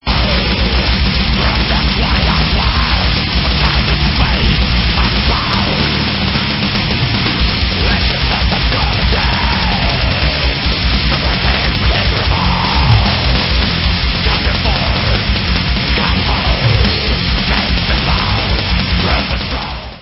sledovat novinky v oddělení Black Metal